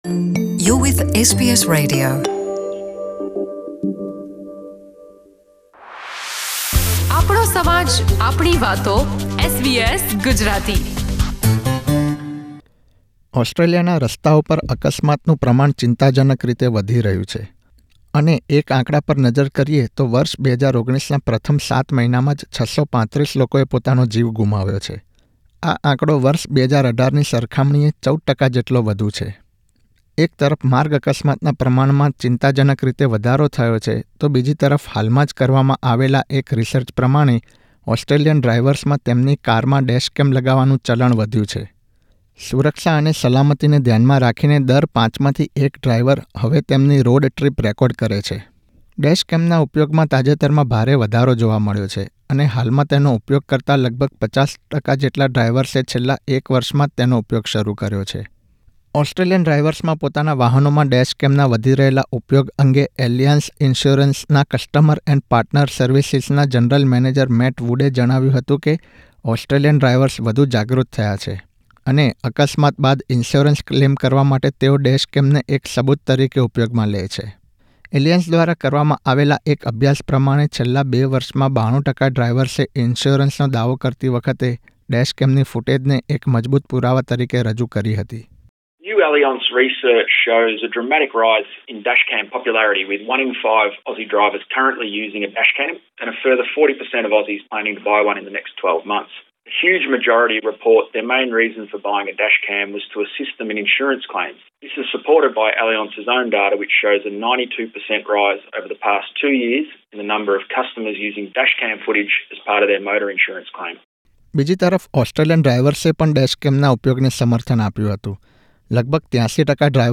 સુરક્ષા અને સલામતીને ધ્યાનમાં રાખીને દર પાંચમાંથી એક ડ્રાઇવર હવે તેમની રોડ ટ્રીપ રેકોર્ડ કરે છે. ડેશ કેમના વધી રહેલા ઉપયોગ અને તેના ફાયદા વિશે SBS Gujarati એ રોડ સેફ્ટી વિશેષજ્ઞ સાથે વાતચીત કરી હતી.